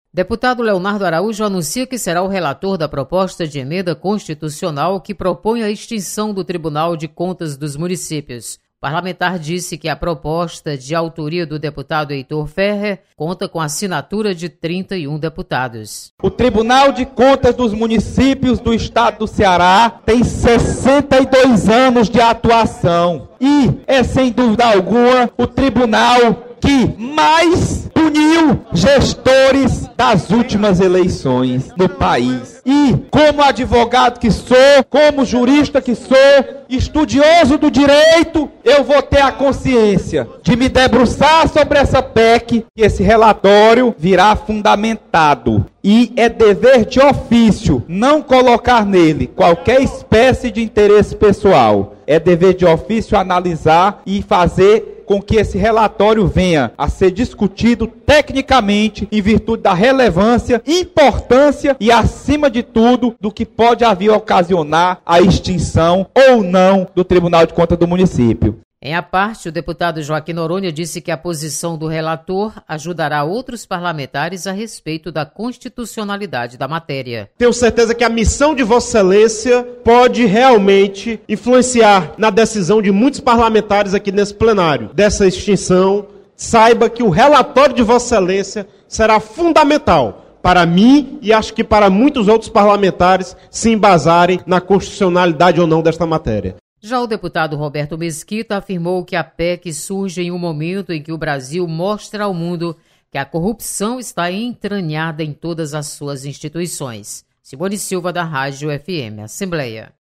Deputados voltam a debater sobre PEC que prevê extinção do TCM. Repórter